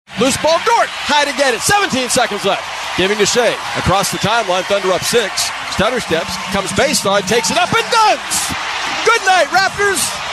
This dunk from Shai Gilgeous-Alexander put an exclamation point on the win in the final seconds, as heard on Sports Talk 99.1 FM - KPGM.